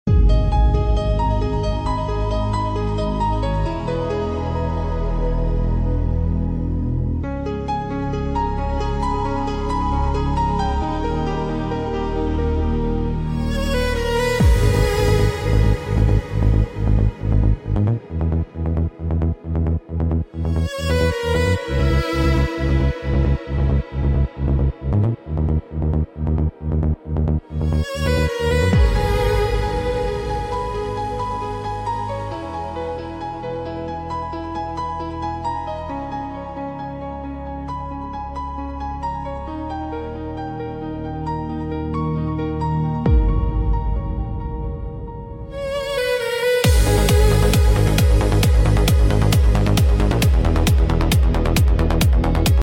МУЗЫКАЛЬНАЯ ЗАСТАВКА